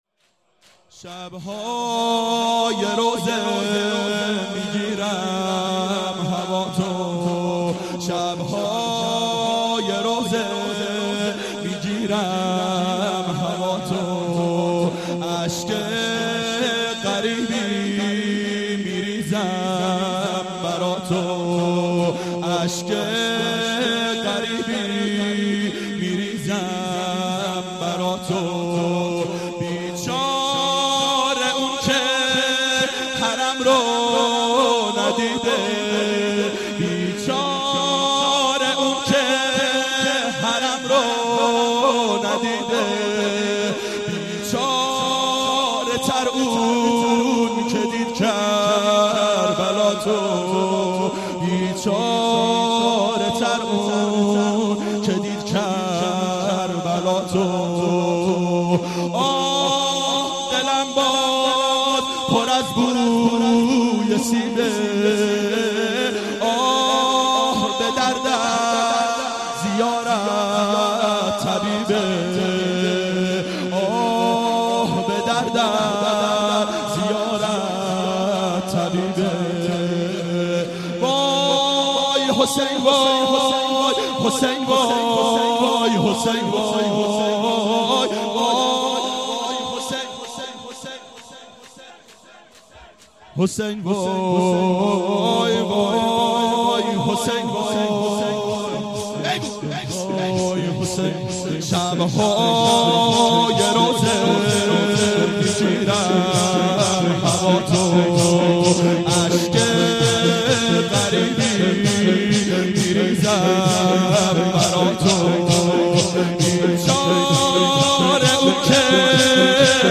• دهه اول صفر سال 1391 هیئت شیفتگان حضرت رقیه سلام الله علیها (شام غریبان)